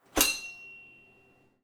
SWORD_02.wav